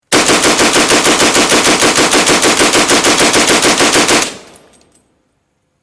Free MP3 military weapons sound effects 2
Weapons Soundbank